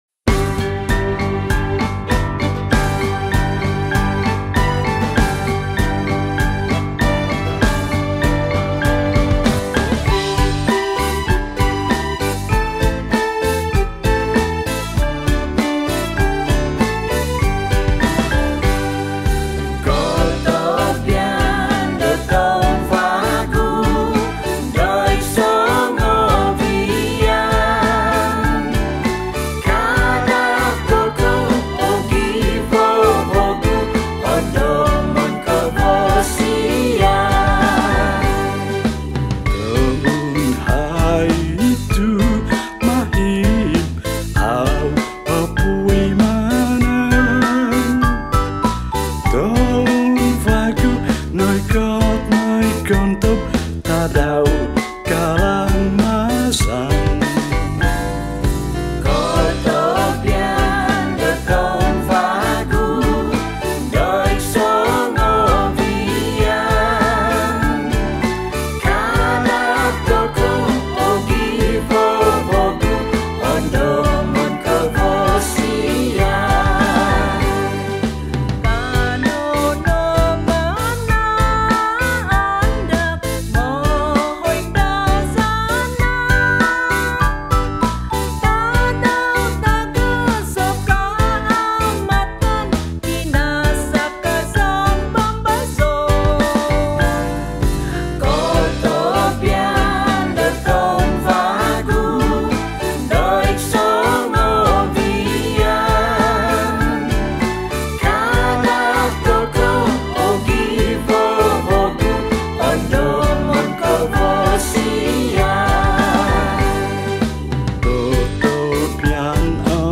Kadazan Songs
Kaamatan Festival Song